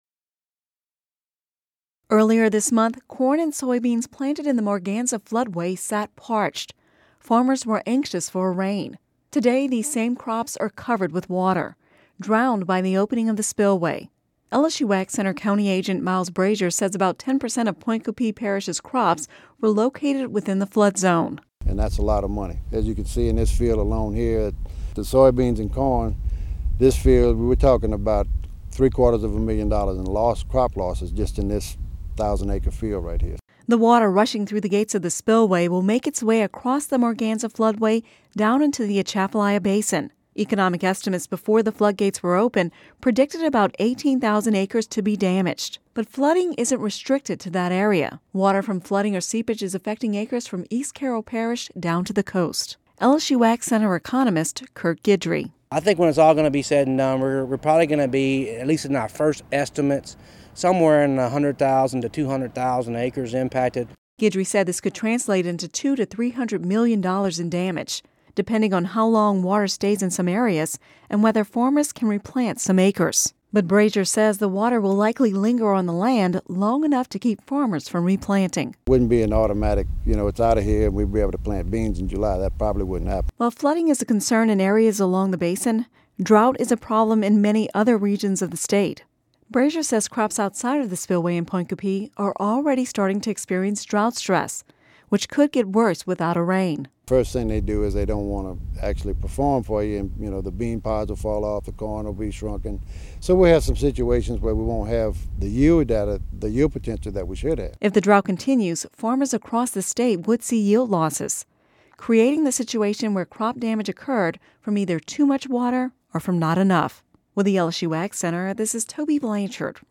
(Radio News 05/18/11) Earlier this month, corn and soybeans planted in the Morganza floodway sat parched.